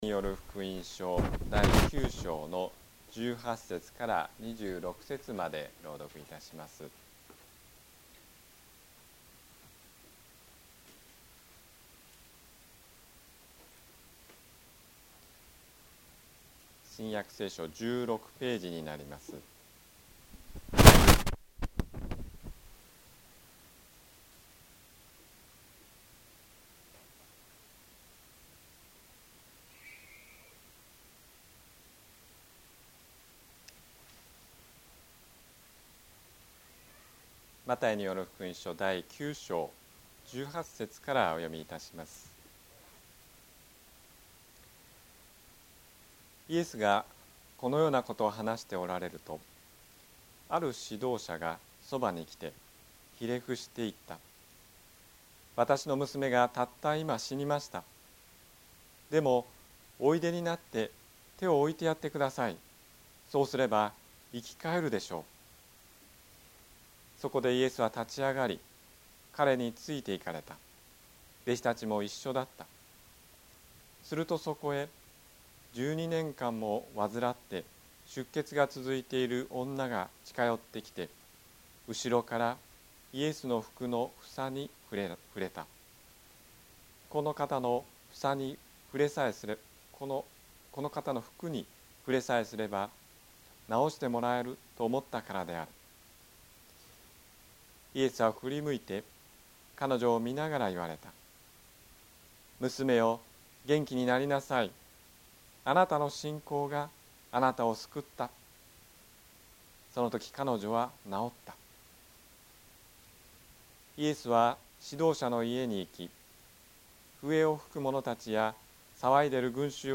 ホームページで礼拝説教の音声データを公開しています。